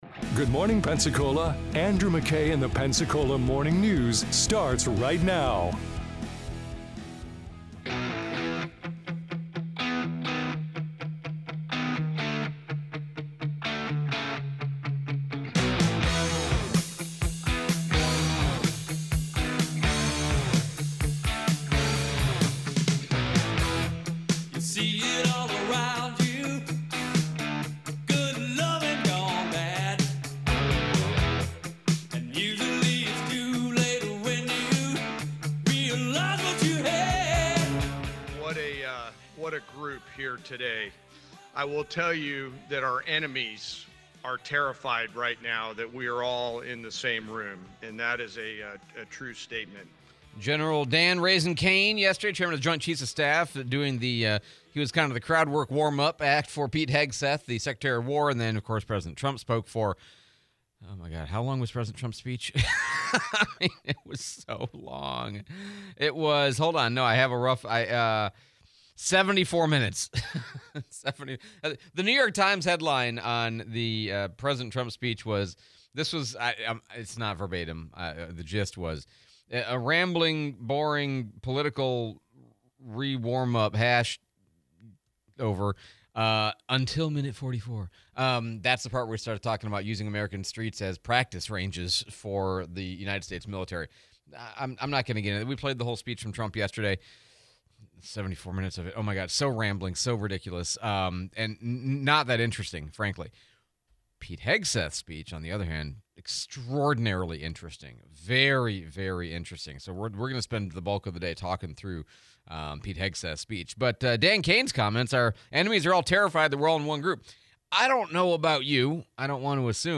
Sec. of War speech, Replay of Sheriff Chip Simmons